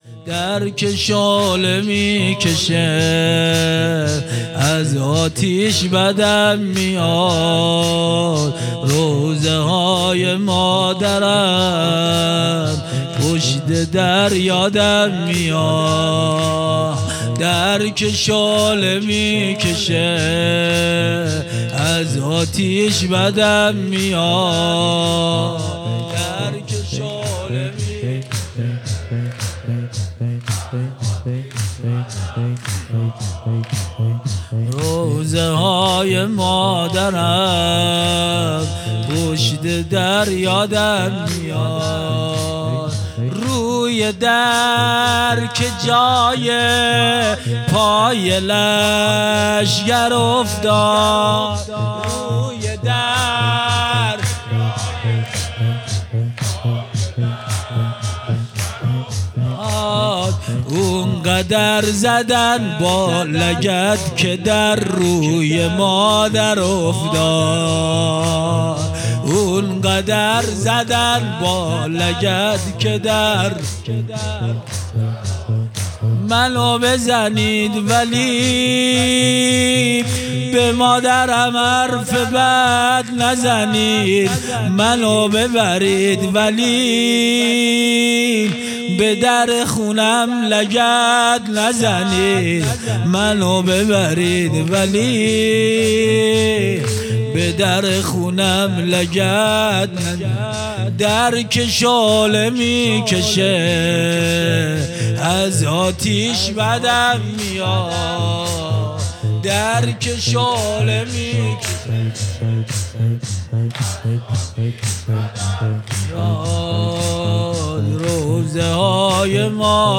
هیئت محبان الحسین علیه السلام مسگرآباد